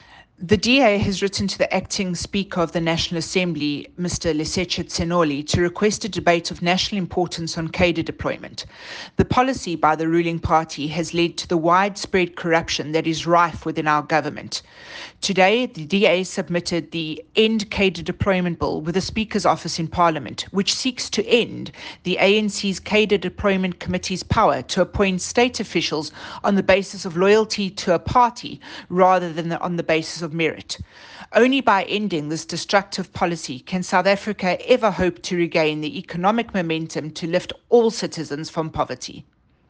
soundbite by Natasha Mazzone MP.